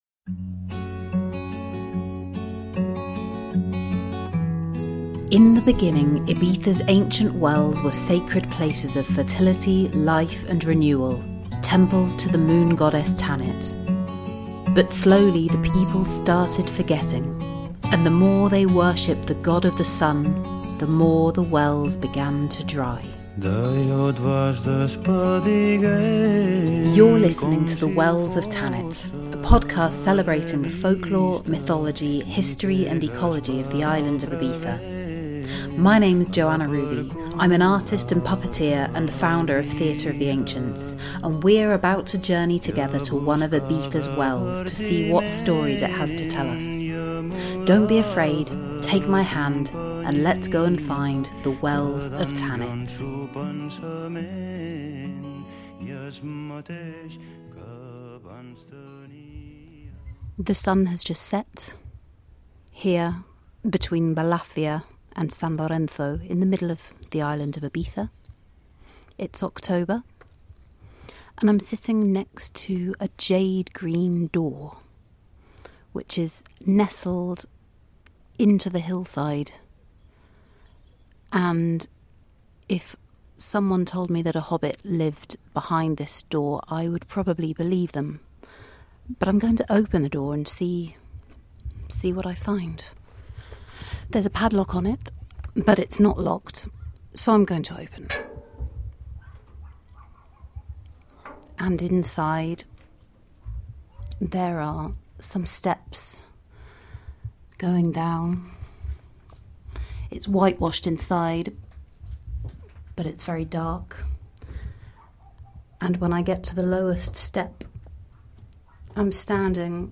This is the question I ponder over in Episode Five, at an enchanted well with a jade green door, the Font de Can Pere Musson near San Lorenzo, Ibiza.